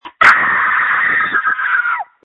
• When you call, we record you making sounds. Hopefully screaming.